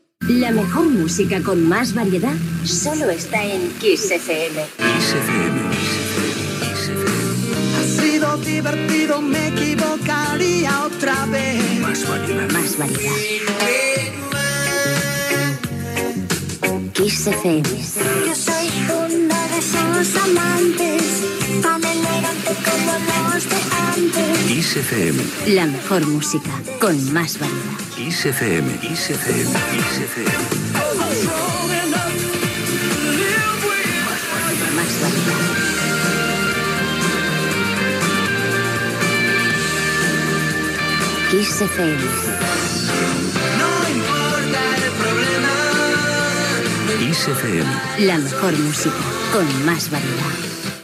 Jingles Radio